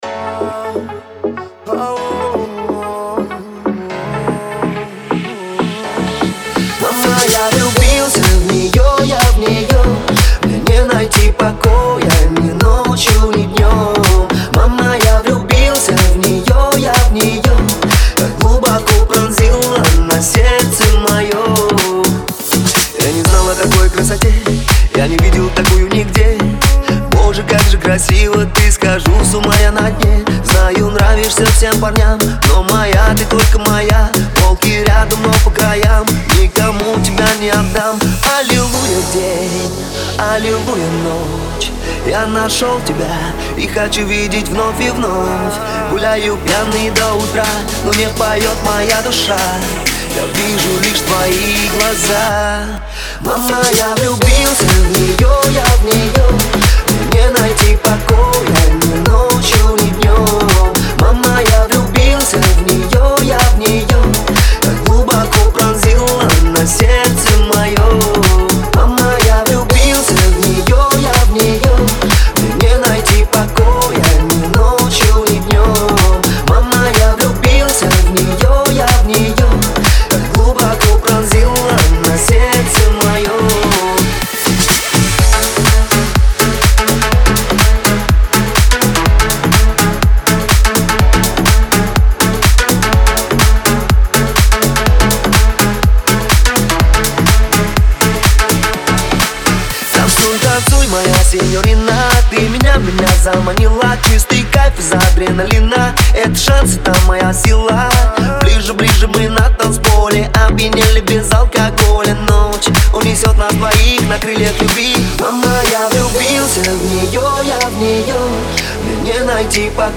мелодичный вокал